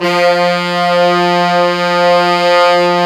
Index of /90_sSampleCDs/Roland LCDP06 Brass Sections/BRS_Pop Section/BRS_Pop Section1